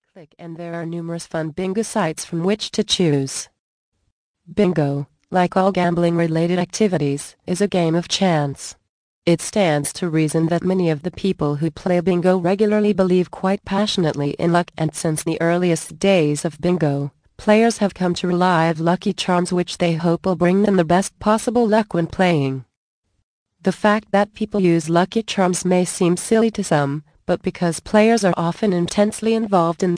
Bingo Winning Secrets. Audio Book. Vol. 2 of 7. 60 min.